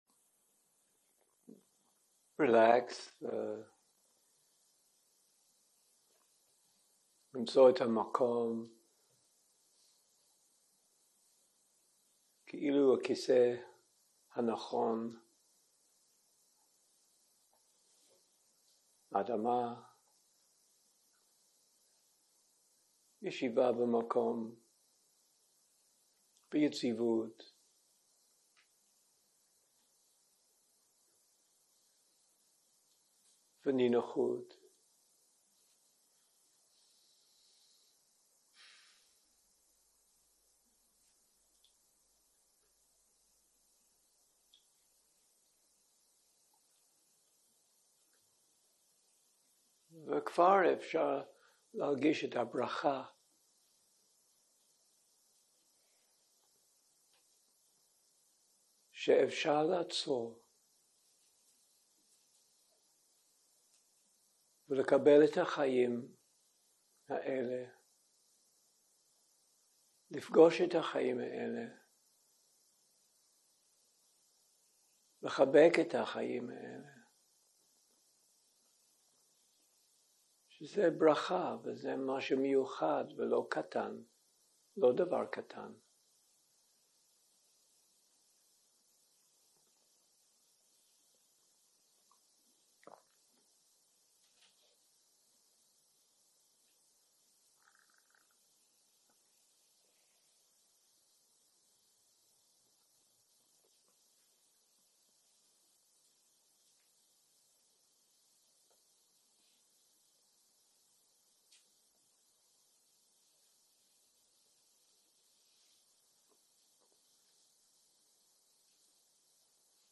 הקלטה 12 - יום 5 - צהרים - מדיטציה מונחית - התיידדות עם עצמי, מטא לעצמי ולדמות מיטיבה 0:00 0:00 סוג ההקלטה: Dharma type: Guided meditation שפת ההקלטה: Dharma talk language: English